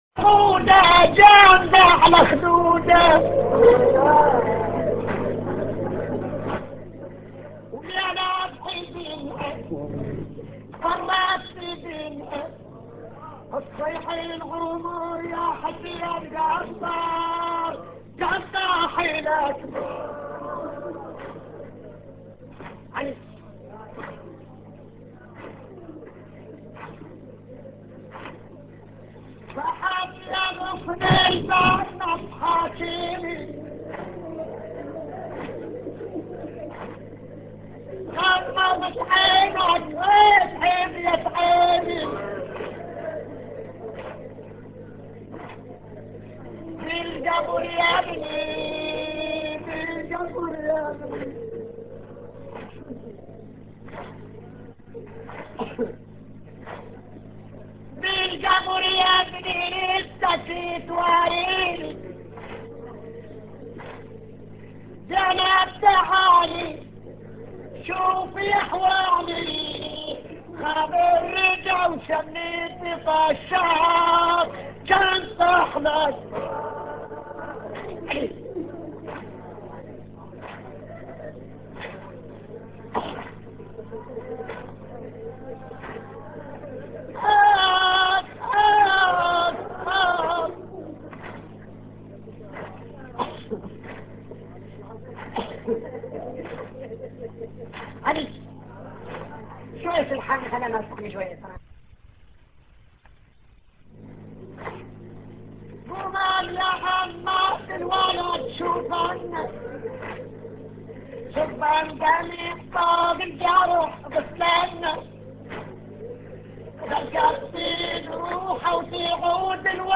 نواعي حسينية